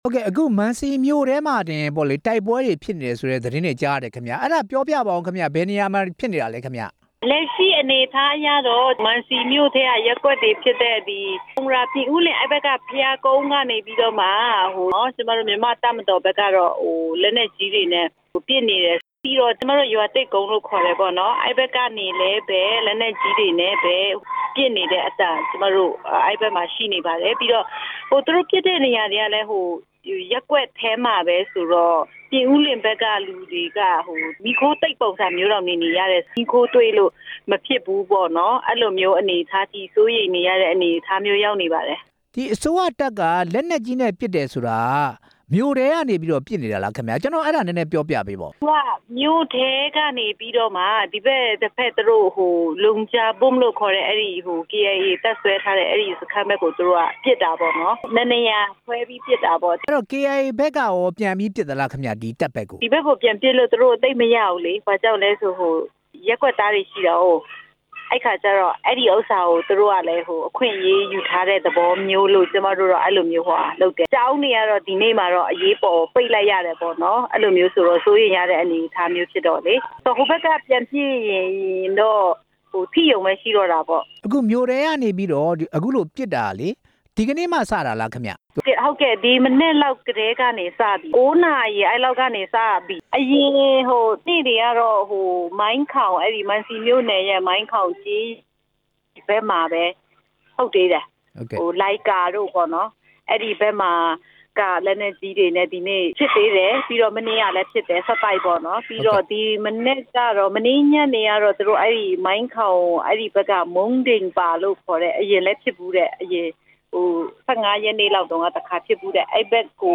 ကချင်ပြည်နယ် တိုက်ပွဲ စောင့်ကြည့်ရေးအဖွဲ့နဲ့ ဆက်သွယ်မေးမြန်းချက်